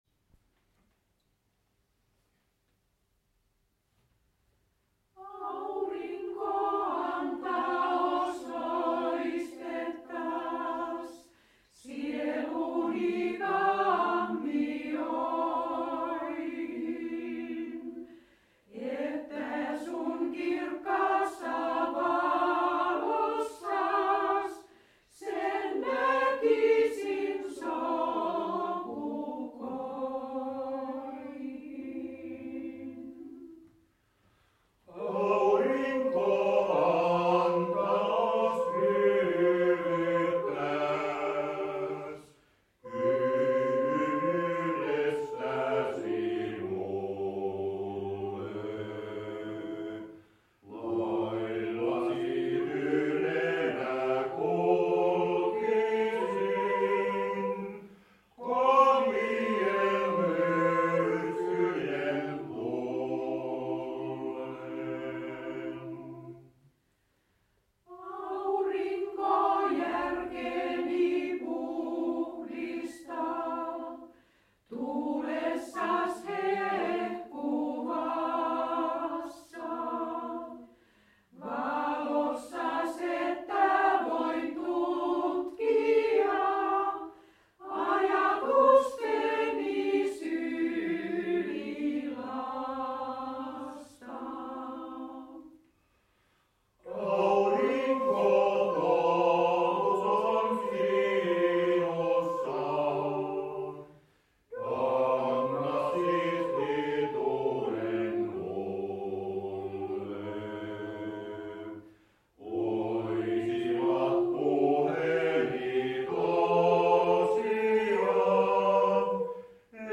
Musiikkiesityksiä; kuoro, yksinlauluja